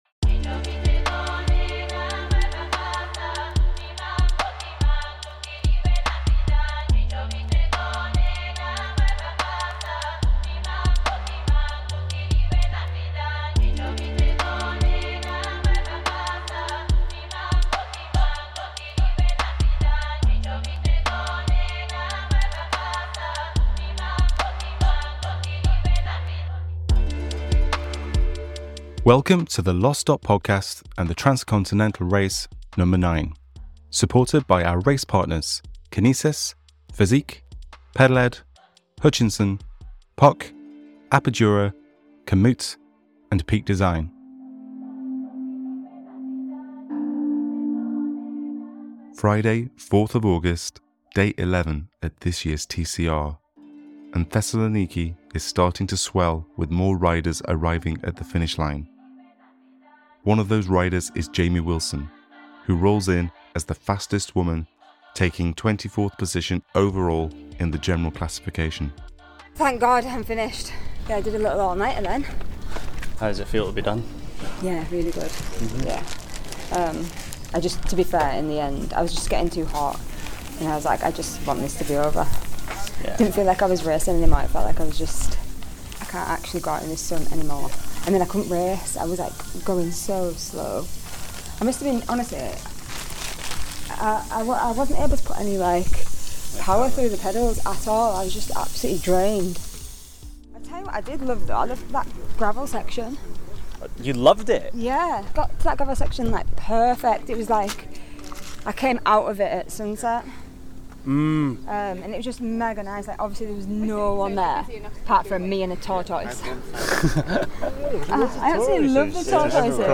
catches up with more riders at the Finish who reflect upon their Race.